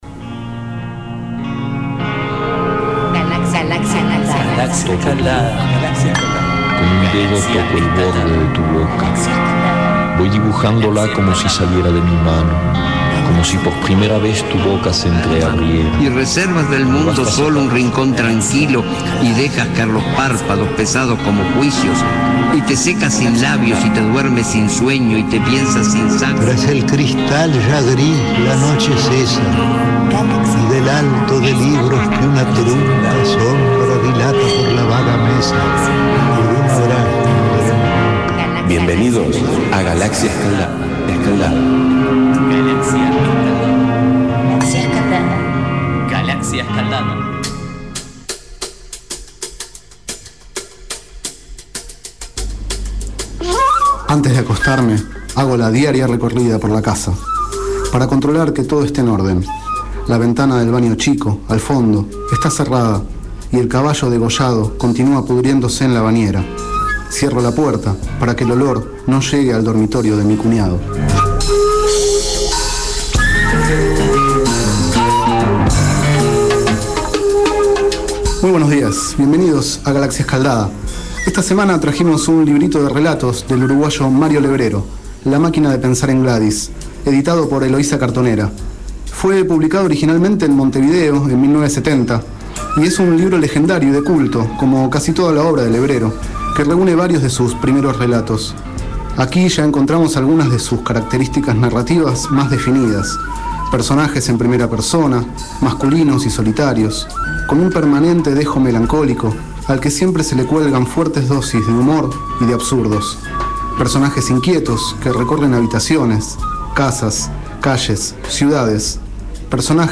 2º micro radial, sobre el libro La máquina de pensar en Gladys, de Mario Levrero.
Este es el 2º micro radial, emitido en los programas Enredados, de la Red de Cultura de Boedo, y En Ayunas, el mañanero de Boedo, por FMBoedo, realizado el 12 de marzo de 2012, sobre el libro La máquina de pensar en Gladys, de Mario Levrero.